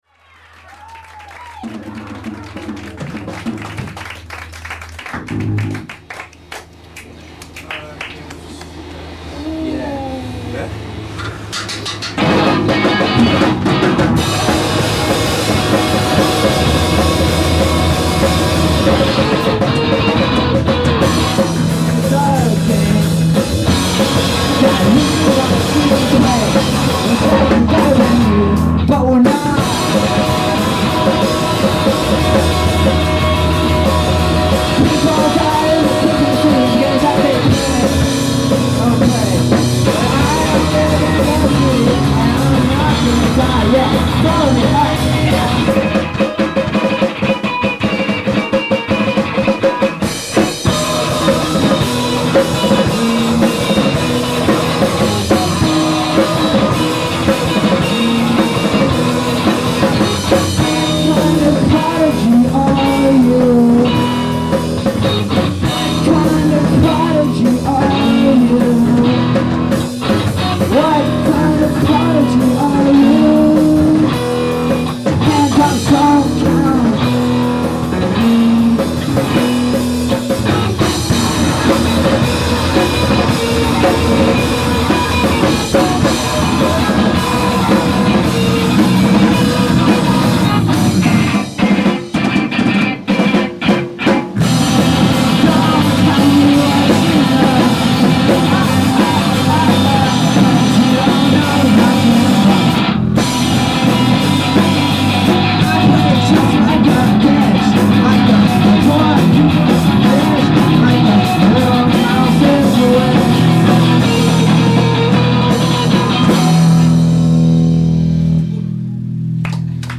bass
drums
guitar + vocals
from shed show